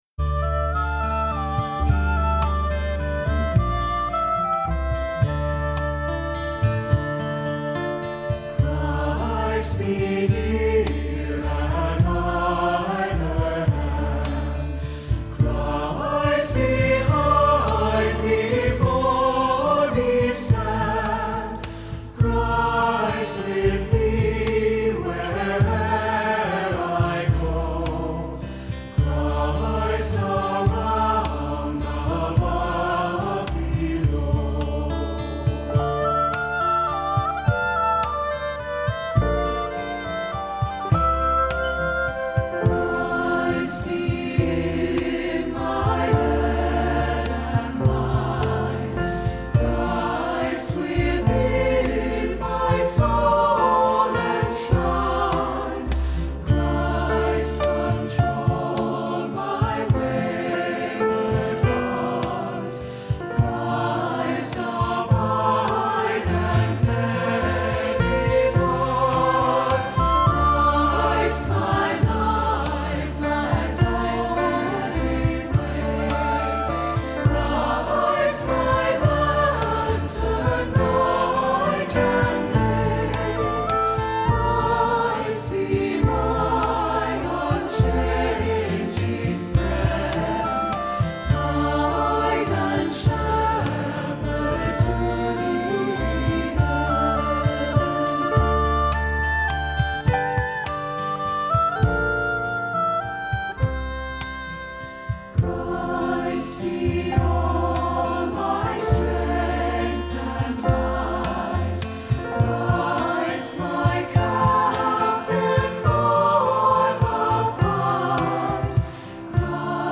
Accompaniment:      Keyboard, Flute;Oboe
Music Category:      Christian